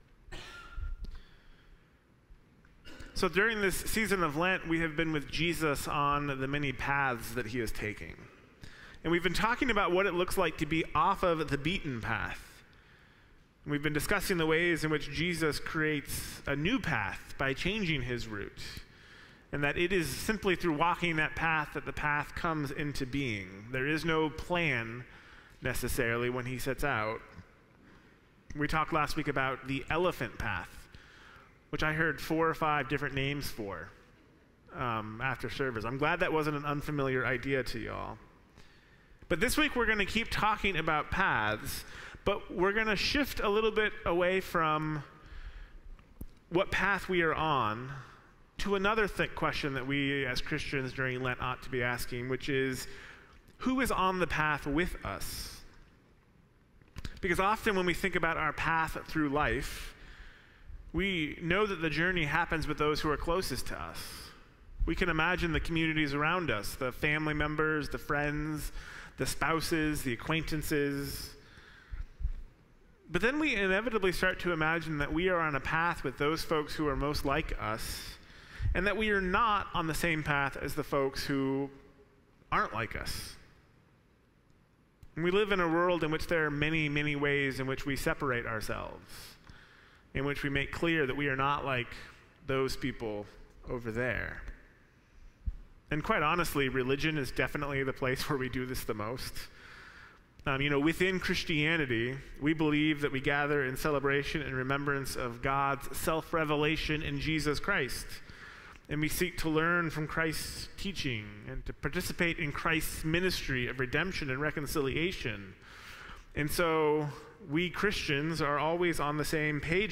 Sermons | First Christian Church